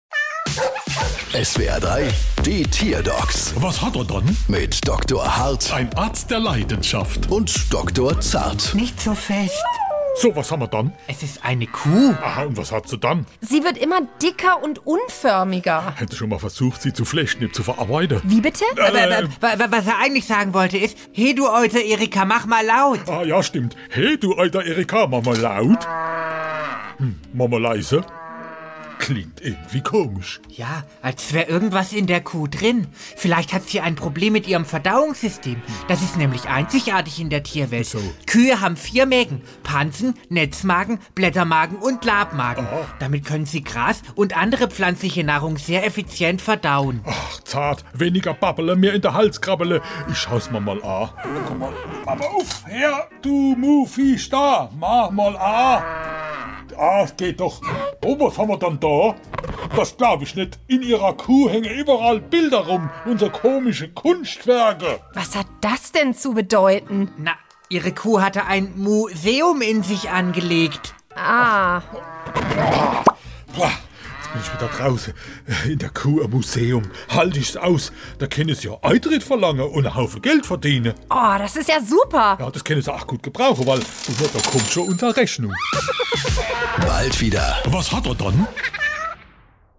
SWR3 Comedy Die Tierdocs: Kuh hortet Gegenstände